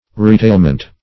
Retailment \Re*tail"ment\, n. The act of retailing.